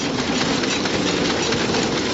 ttr_s_ara_chq_trolleyloop.ogg